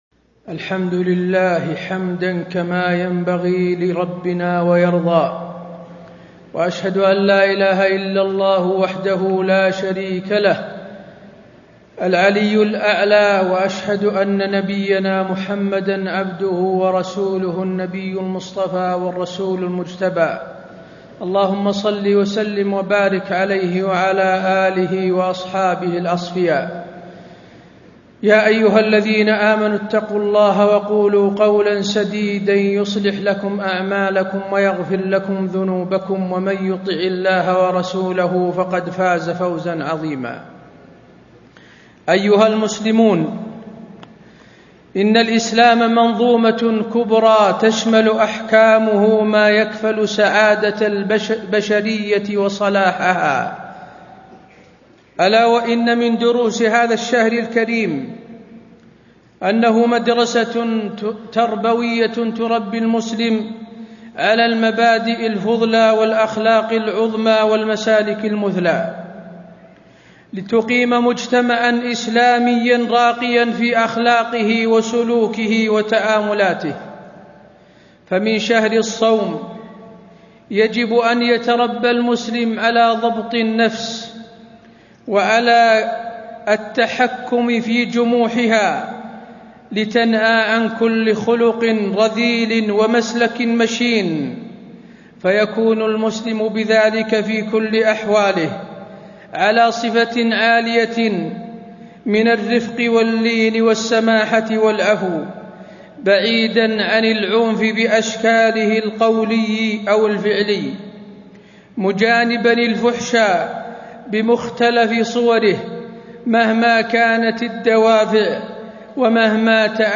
تاريخ النشر ١٣ رمضان ١٤٣٥ هـ المكان: المسجد النبوي الشيخ: فضيلة الشيخ د. حسين بن عبدالعزيز آل الشيخ فضيلة الشيخ د. حسين بن عبدالعزيز آل الشيخ رمضان وتهذيب الأخلاق